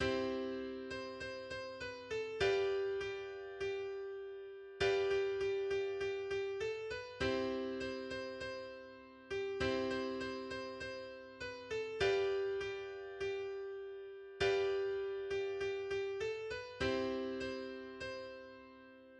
A slightly different tune used to be more common in Germany and still prevails in Western Austria and the German speaking part of Switzerland:
Note that the ambitus is only a fourth in the latter but a Minor seventh in the former Variation.